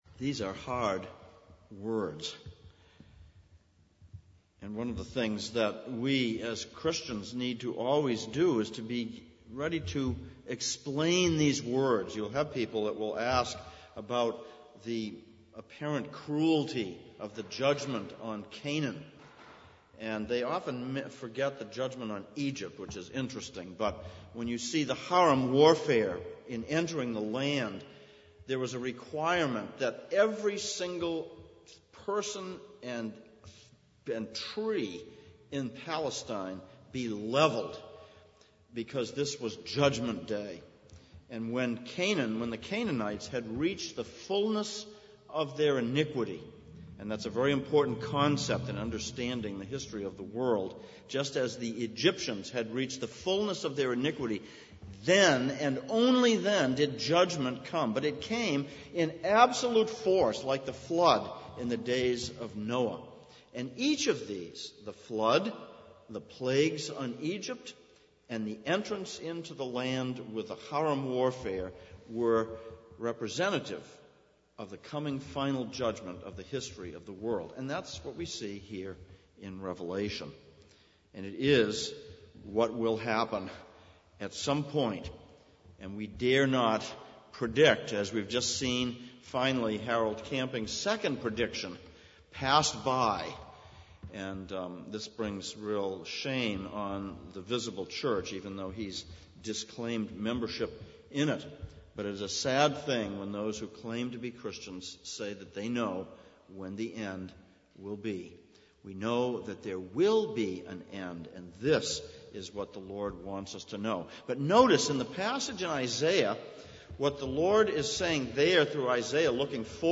Passage: Revelation 16:1-9, Isaiah 49:13-26 Service Type: Sunday Evening